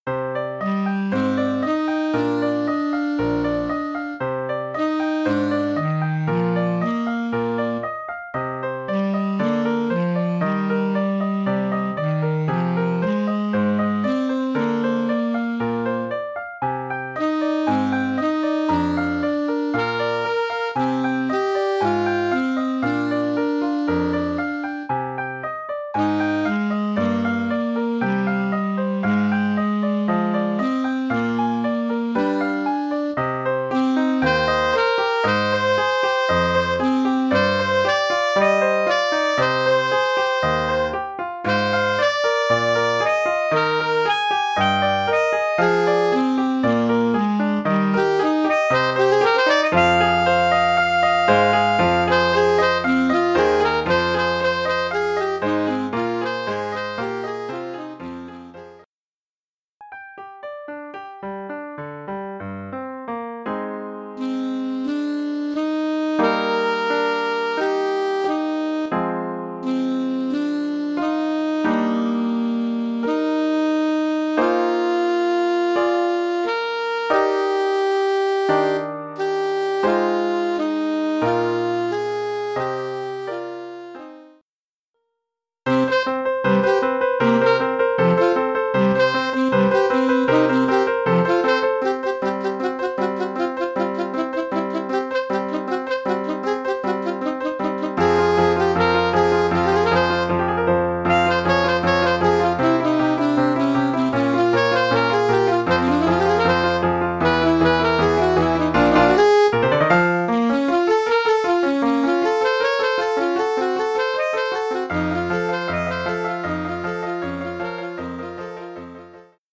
Formule instrumentale : Saxophone alto et piano
Oeuvre pour saxophone alto et piano.